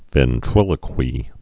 (vĕn-trĭlə-kwē)